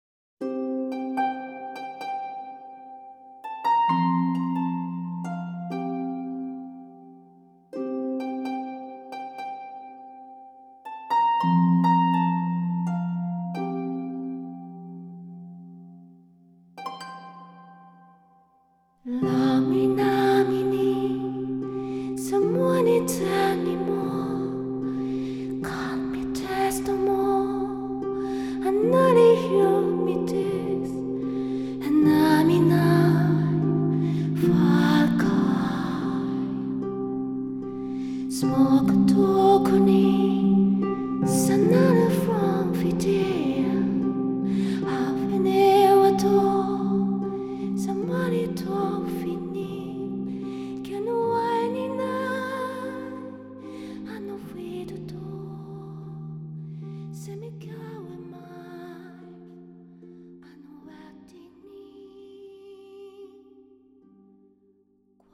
western-influerade tongångar